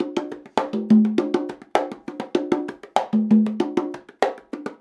100 CONGAS13.wav